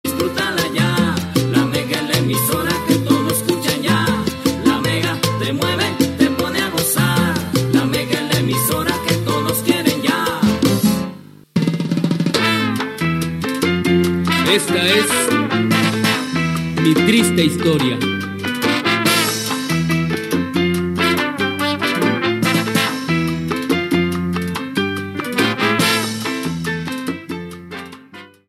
Indicatiu de la ràdio i tema musical